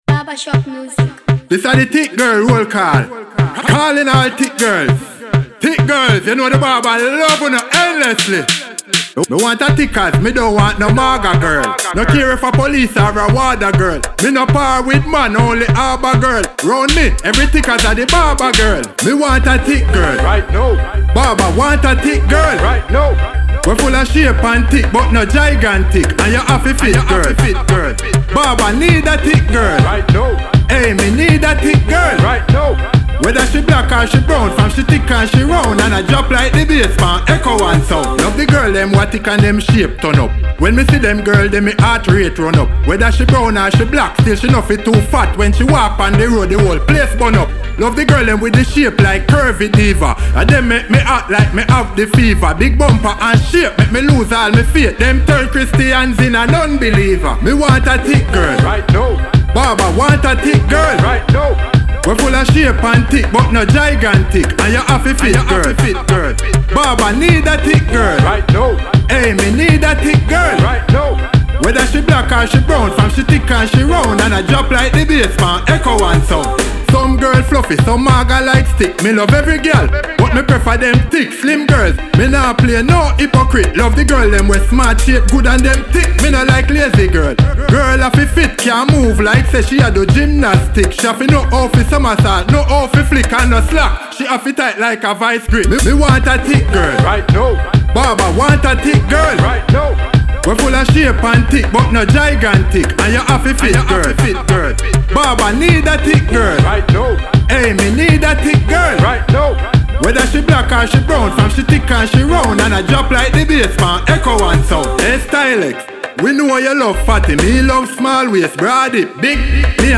dancehall \ reggae